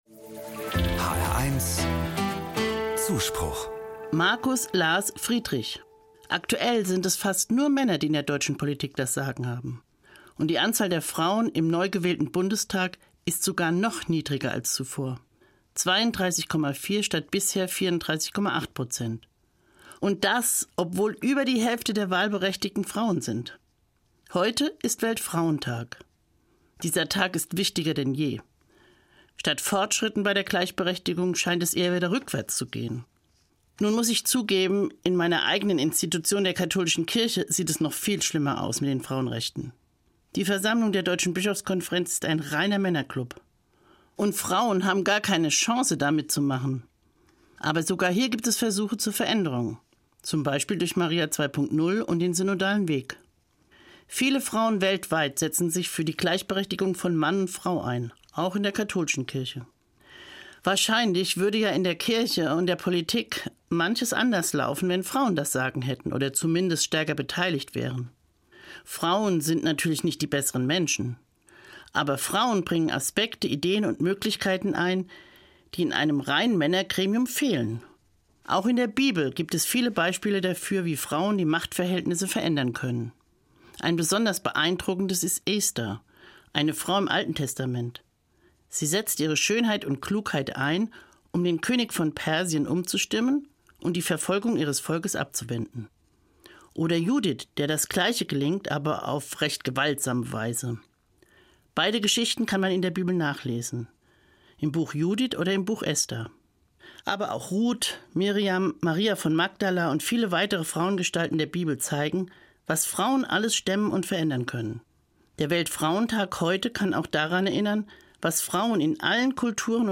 katholische Pastoralreferentin im Ruhestand, Frankfurt